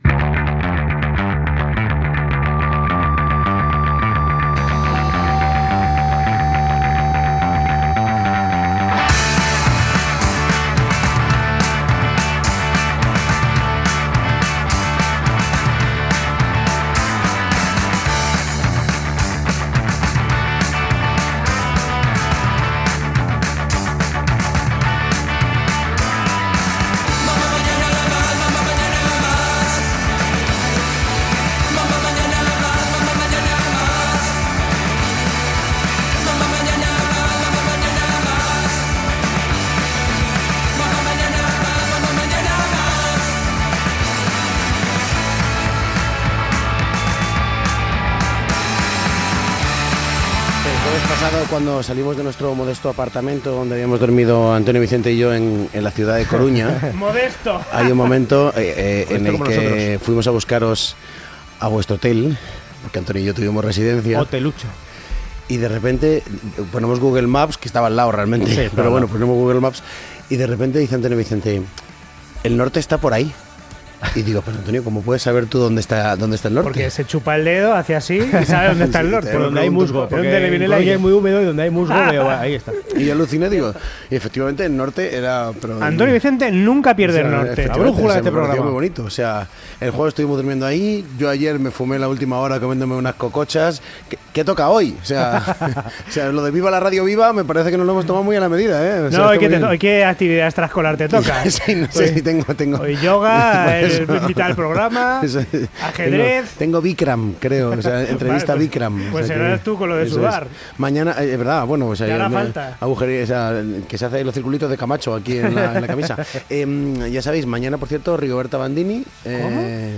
Sintonia, diàleg dels presentadors sobre el fet la nit anterior
Entreteniment